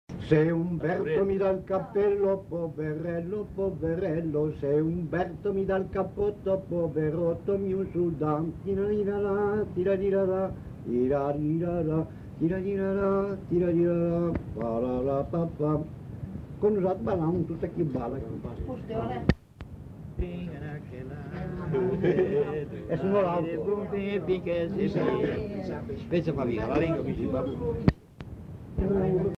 Lieu : Bellino
Genre : chant
Effectif : 1
Type de voix : voix d'homme
Production du son : chanté